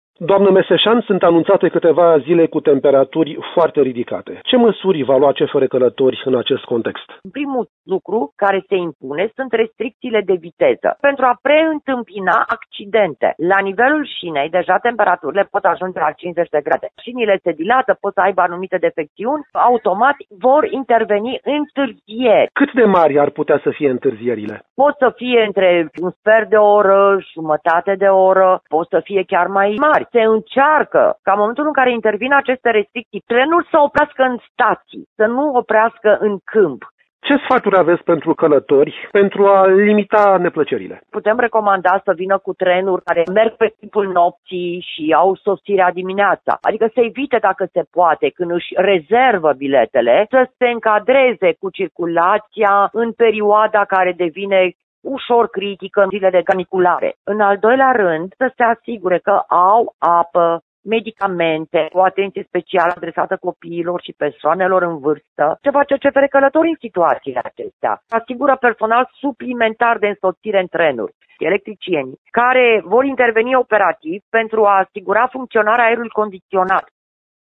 Răspunsurile, în interviul următor